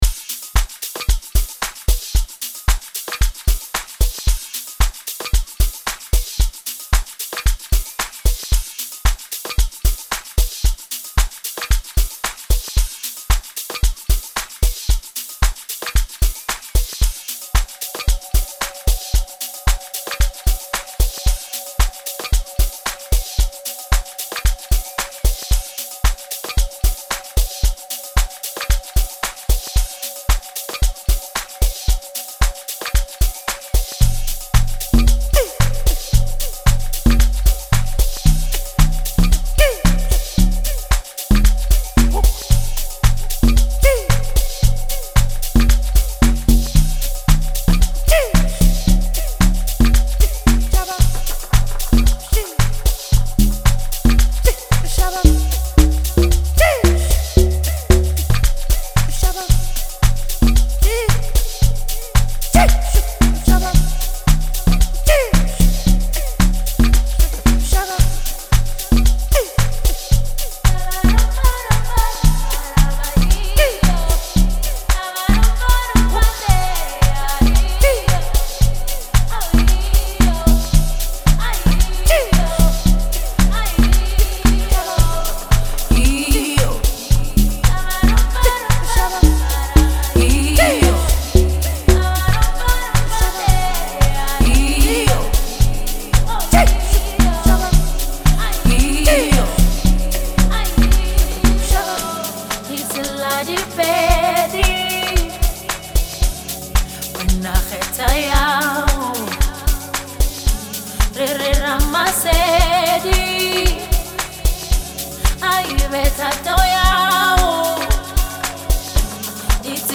expressive and soulful vocals